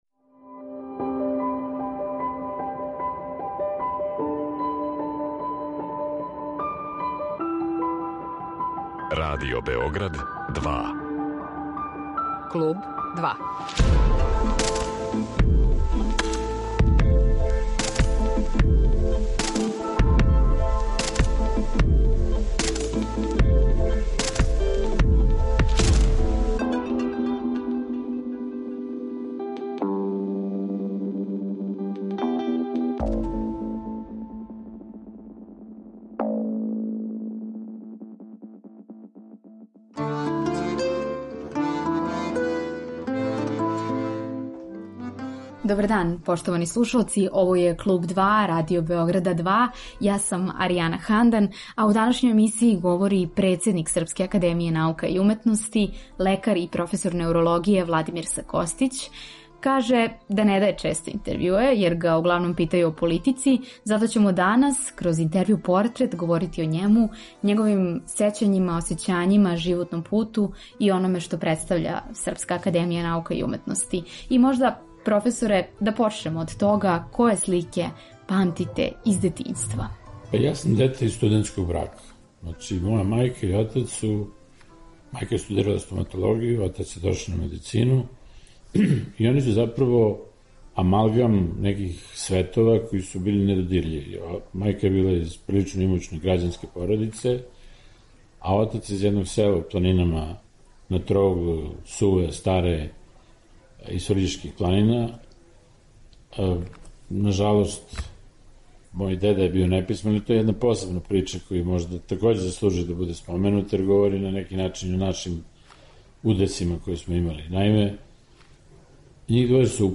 Интервју са председником Српске академије наука и уметности, лекаром и професором неурологије Владимиром С. Kостићем.